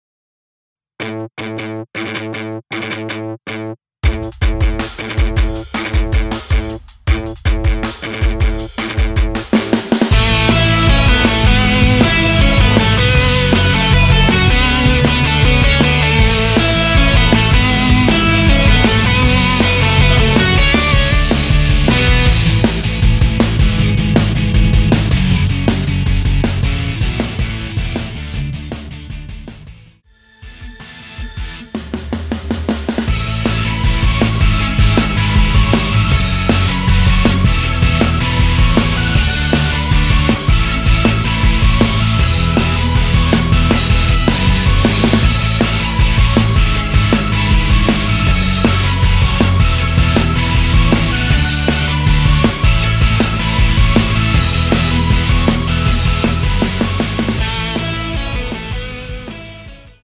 원키에서(-1)내린 MR입니다.
Dm
앞부분30초, 뒷부분30초씩 편집해서 올려 드리고 있습니다.